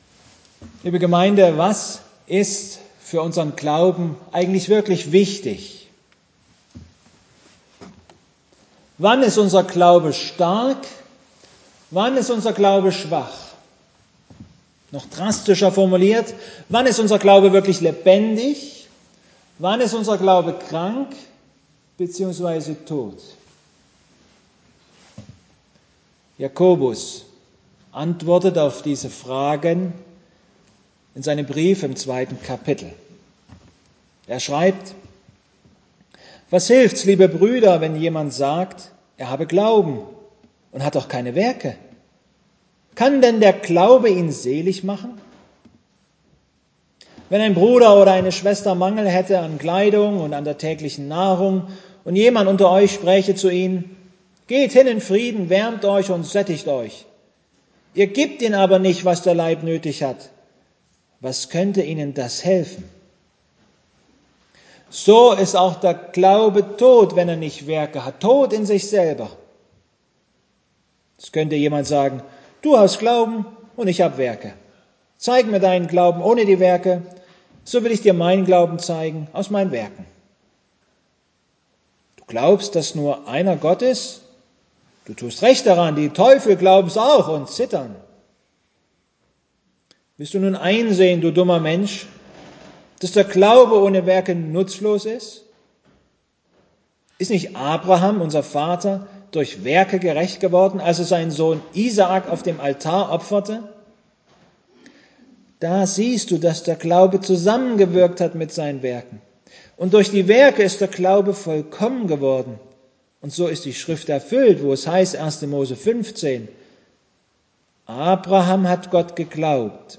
Predigtgottesdienst Obercrinitz Unser Glaube hat unmittelbare Auswirkungen auf unseren Umgang untereinander.